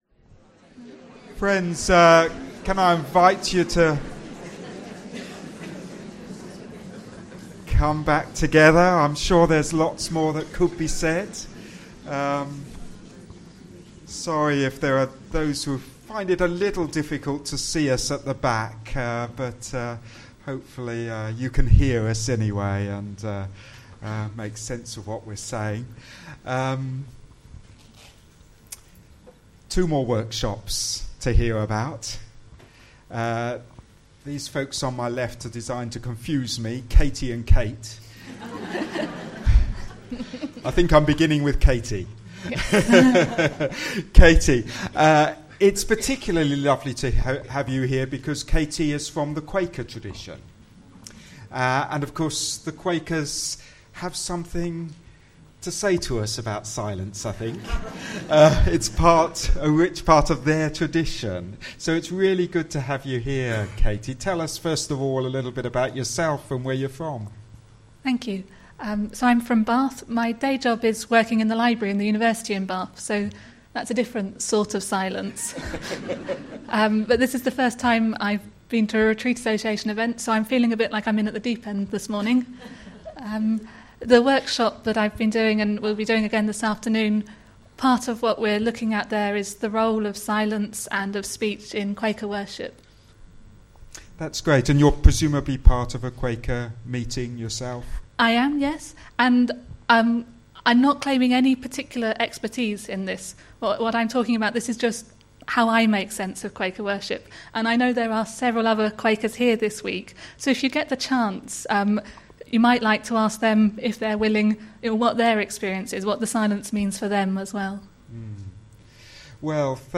I was speaking as part of a panel session at the recent ecumenical conference Sounding the Silence: Exploring depths in stillness and speech .